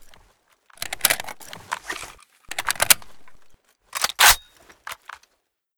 reload_empty.ogg